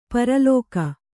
♪ paralōka